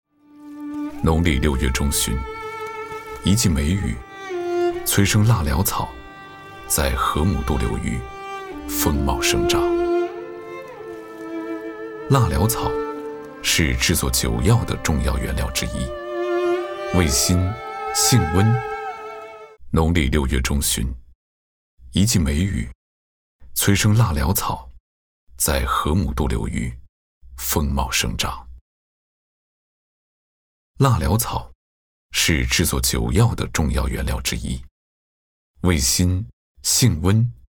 190男-沧桑大气
特点：大气浑厚 稳重磁性 激情力度 成熟厚重
宣传片—【舌尖风】辣蓼草.mp3